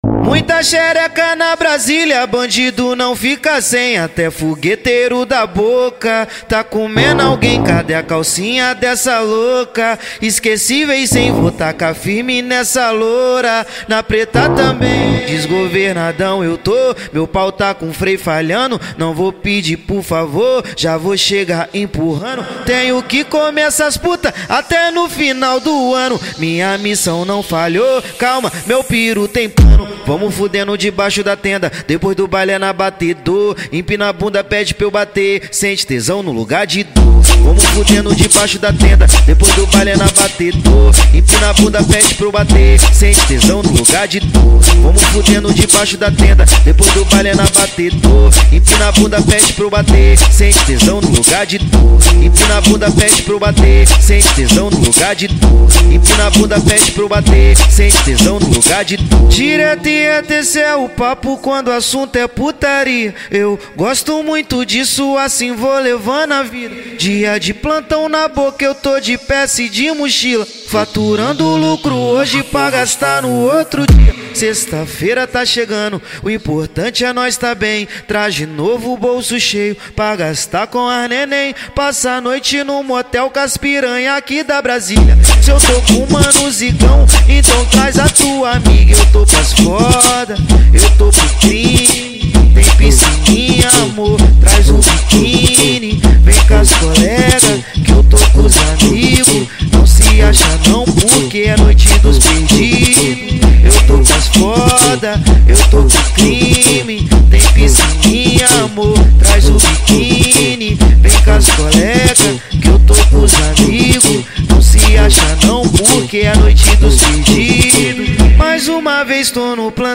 2024-08-15 17:23:07 Gênero: Axé Views